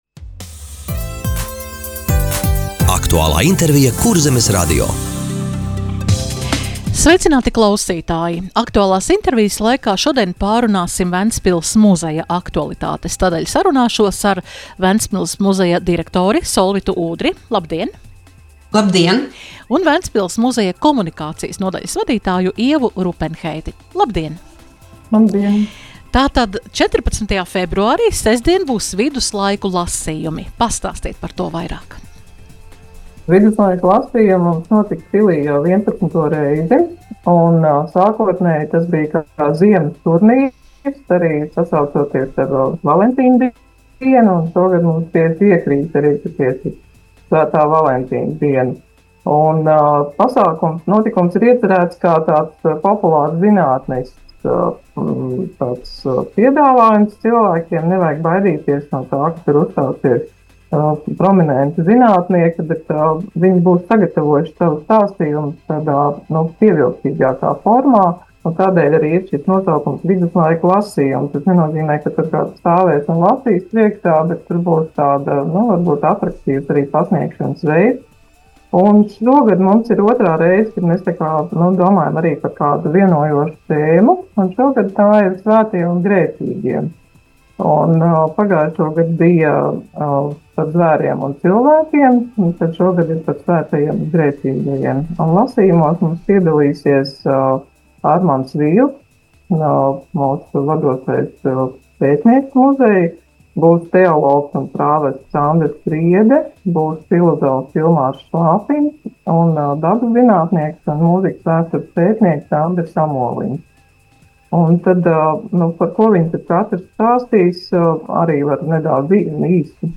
Radio saruna Ventspils Muzeja aktualitātes - Ventspils